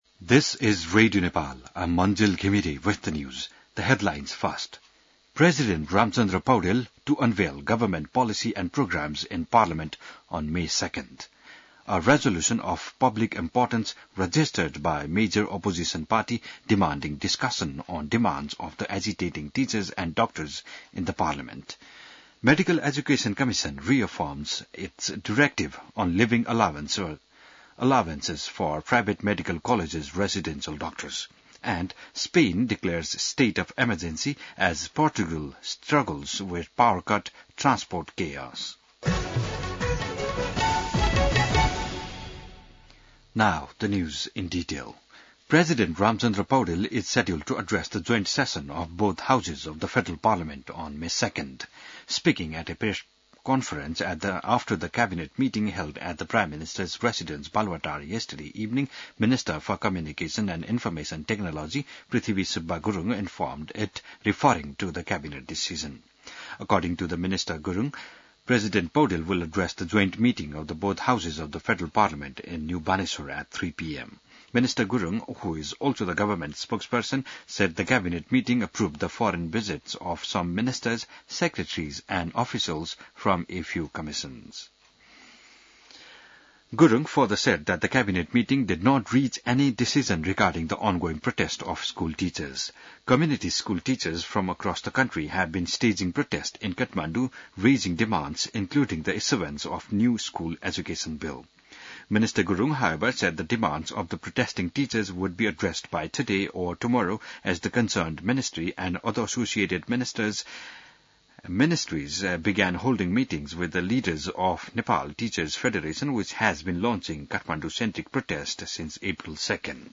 An online outlet of Nepal's national radio broadcaster
बिहान ८ बजेको अङ्ग्रेजी समाचार : १६ वैशाख , २०८२